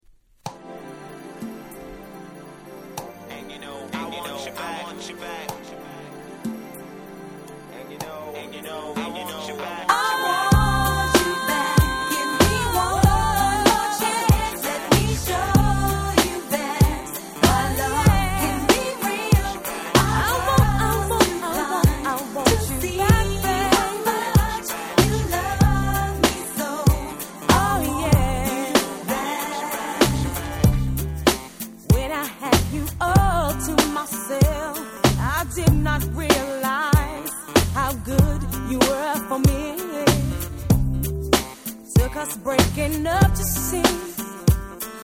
95' Nice Hip Hop Soul !!
「これぞまさにHip Hop Soul !!」な鉄板の展開！
泣きのCメロへの展開は見事の一言！！